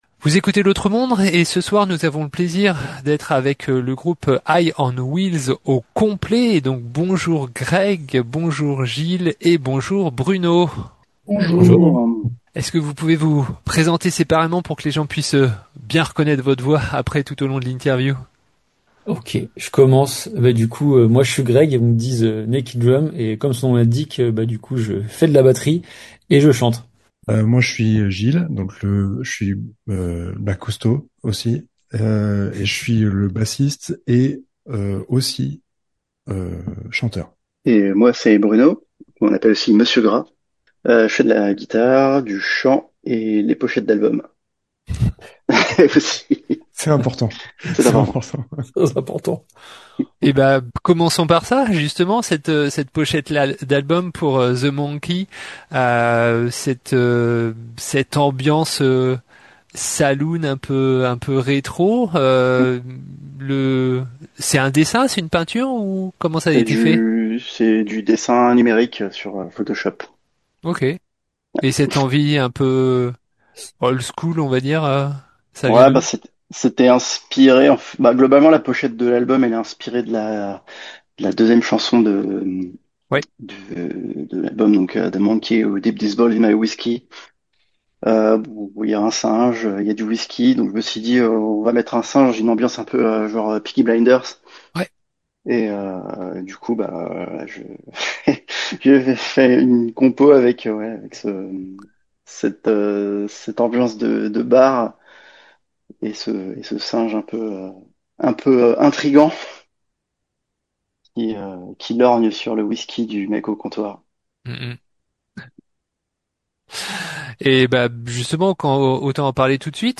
Interview du groupe High on wheels enregistrée le 30.10.2025 pour la sortie de l'album The Monkey